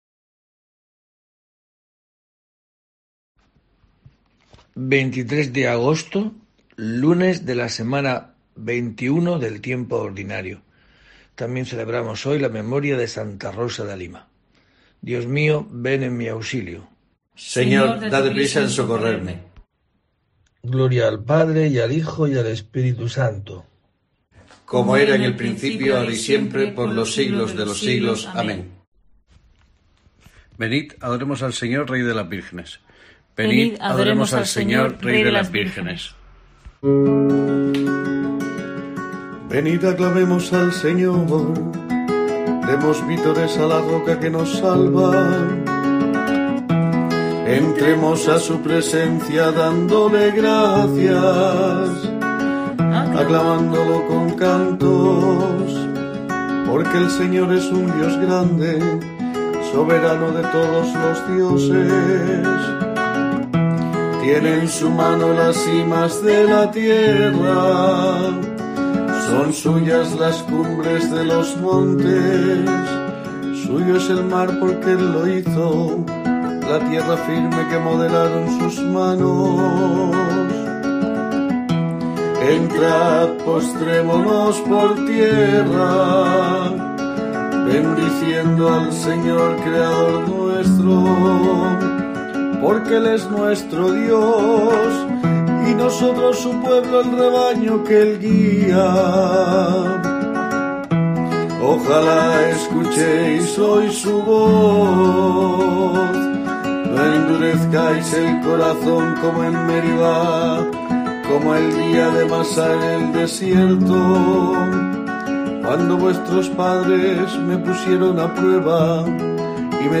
23 de agosto: COPE te trae el rezo diario de los Laudes para acompañarte